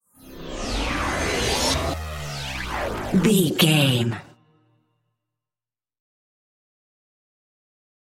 Sound Effects
Atonal
ominous
eerie
synthesiser
ambience
pads